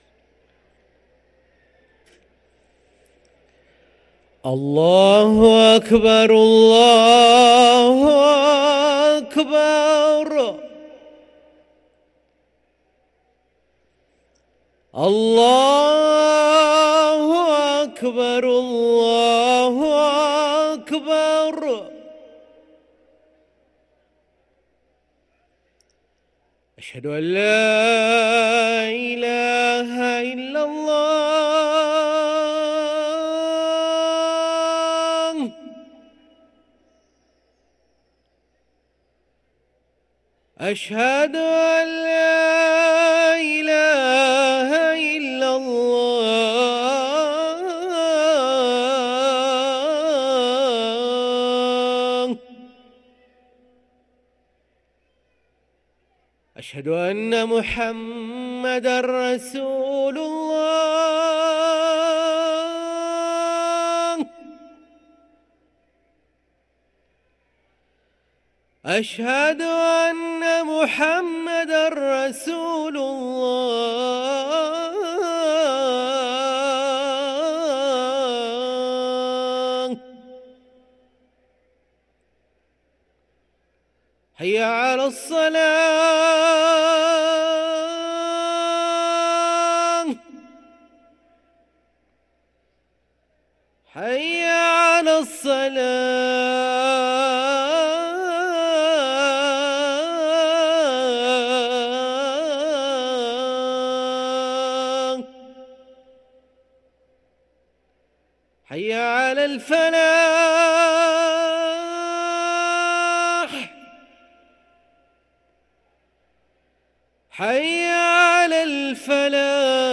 أذان المغرب للمؤذن